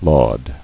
[laud]